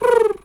pigeon_2_call_04.wav